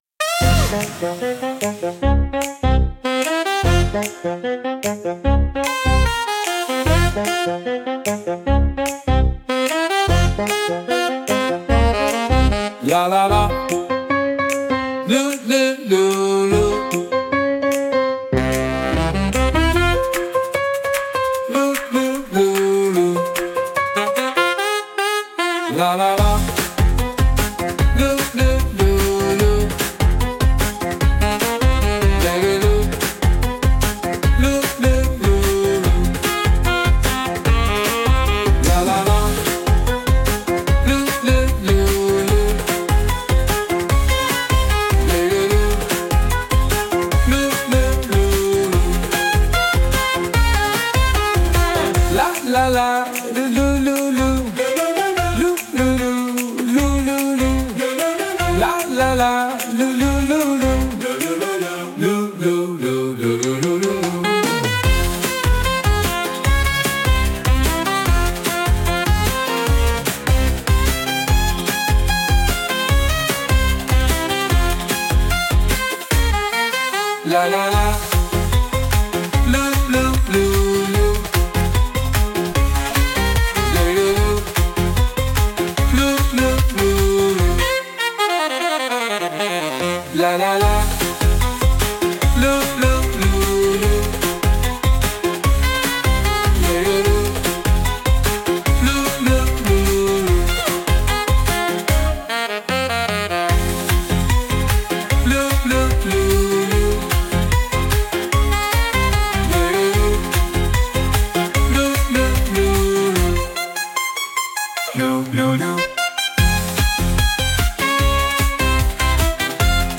With Vocals / 歌あり
リズムが明確で非常に踊りやすく、手具操作のタイミングも合わせやすい、使い勝手抜群の「王道」新体操ポップスです。